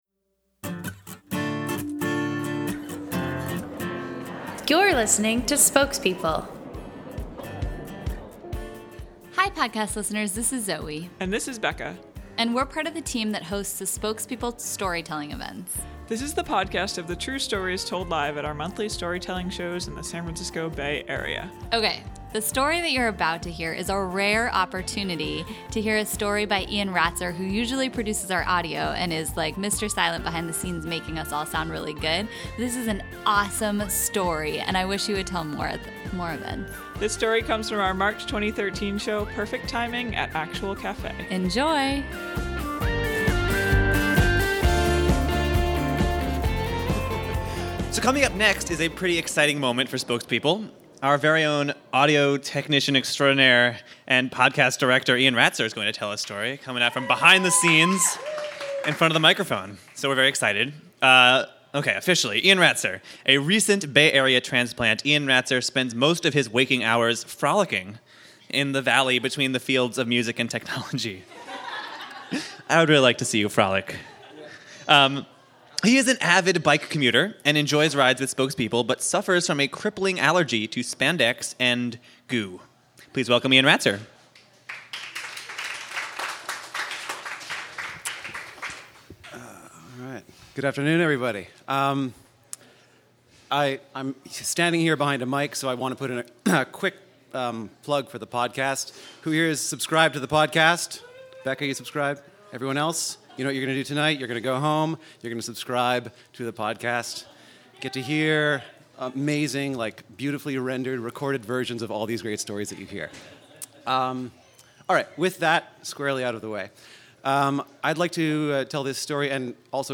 This story of the rhythm section paying homage to the home of rhythm and blues in just the nick of time comes from our March 2013 show, “Perfect Timing.”